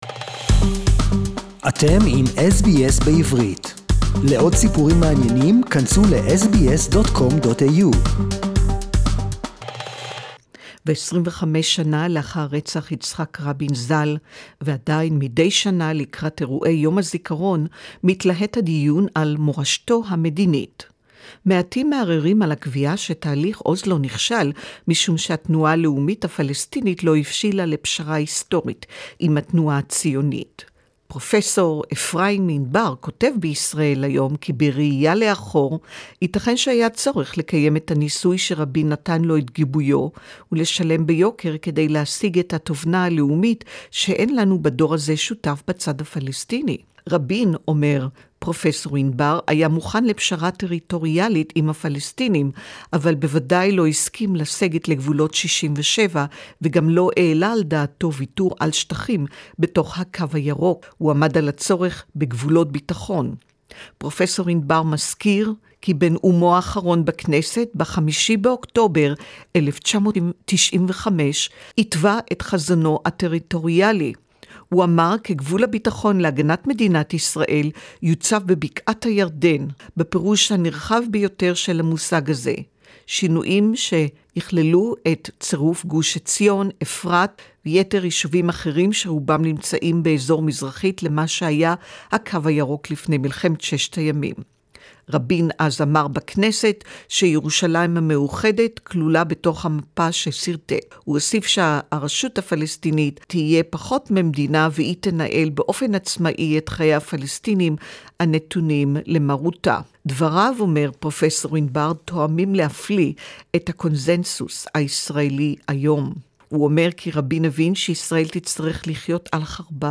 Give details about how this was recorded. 4th November will mark 25 years since the assassination of Israeli Prime Minister Yitzhak Rabin. We will commemorate Rabin with Special recordings from the Israeli media on the night he was murdered.